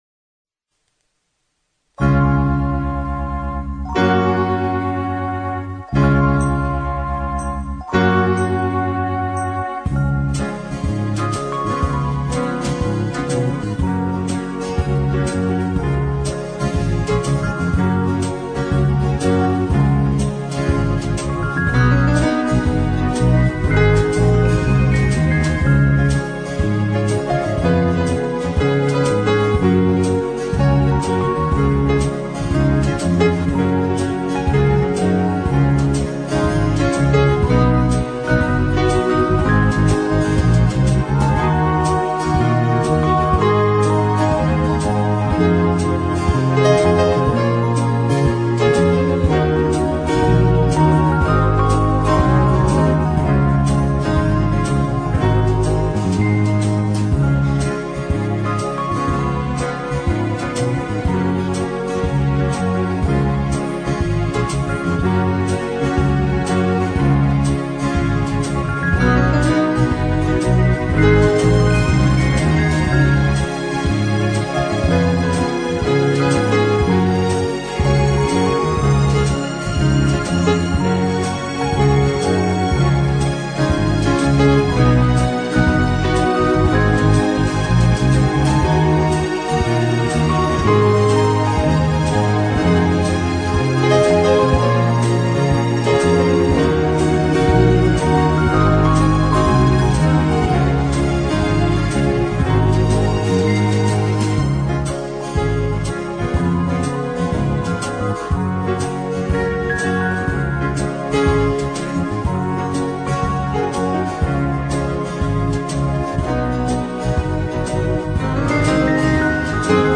en Do# et Ré